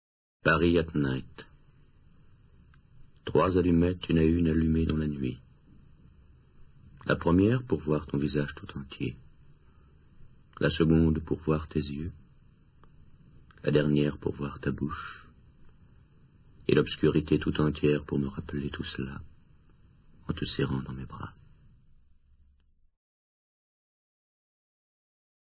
/wp-content/uploads/2011/01/parisatnight.mp3 dit par Serge REGGIANI Jacques PRÉVERT ( Paroles , 1945) ©1972 Editions Gallimard